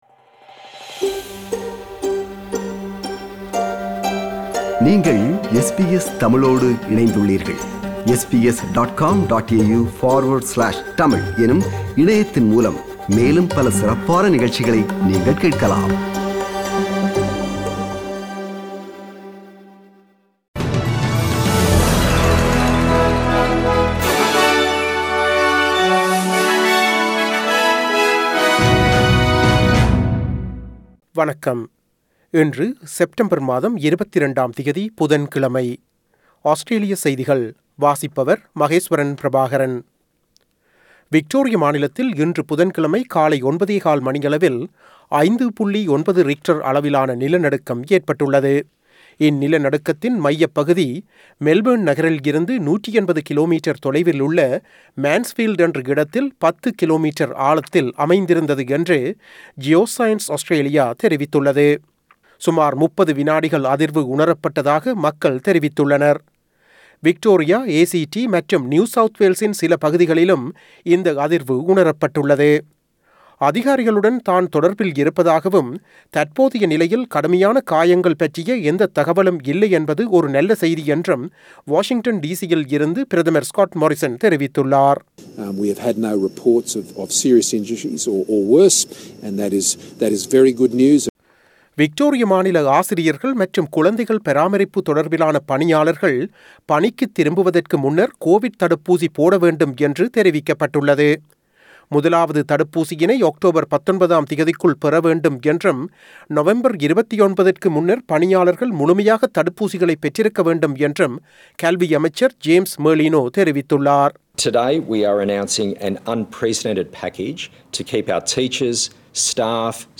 Australian news bulletin for Wednesday 22 September 2021.